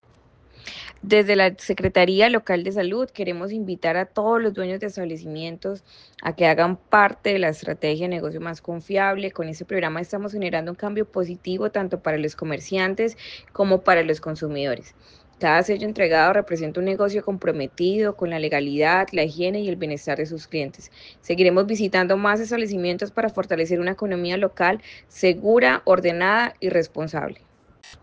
CAMILA BORRERO - SECRETARIA DE SALUD.mp3